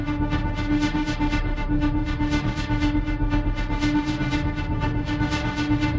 Index of /musicradar/dystopian-drone-samples/Tempo Loops/120bpm
DD_TempoDroneB_120-D.wav